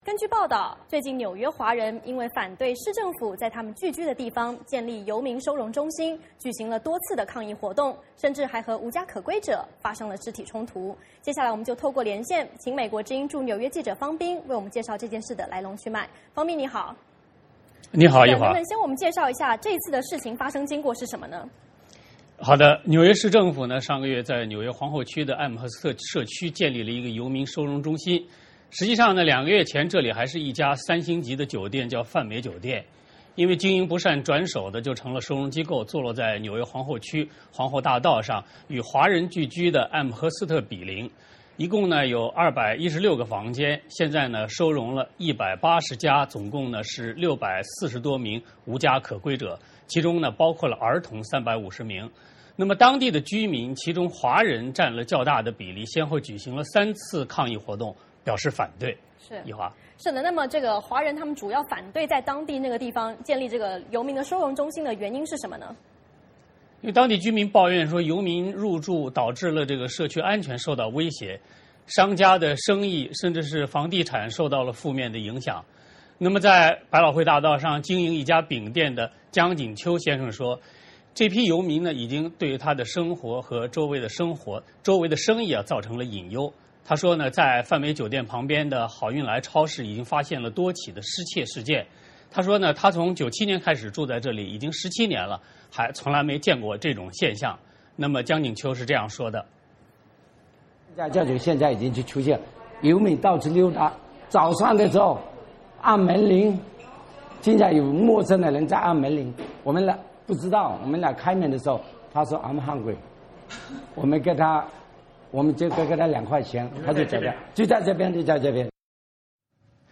VOA连线：纽约市计划在华人聚居地建游民收容所 当地华人进行抗议